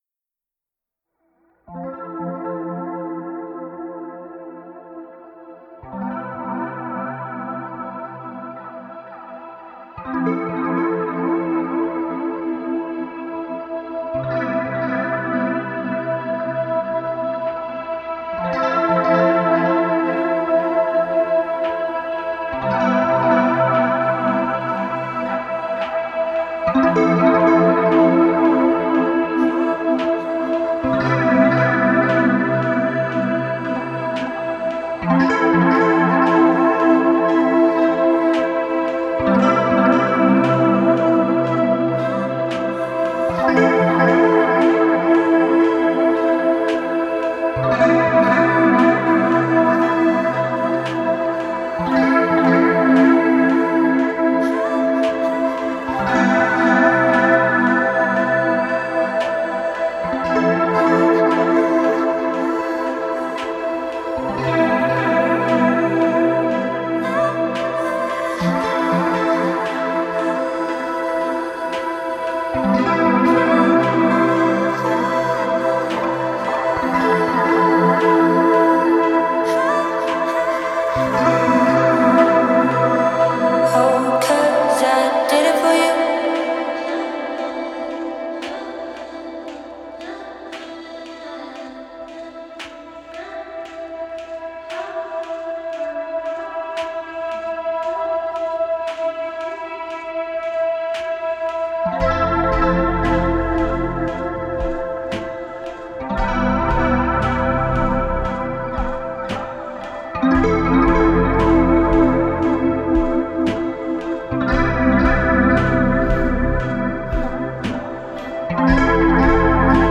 это атмосферная электронная композиция в жанре синти-поп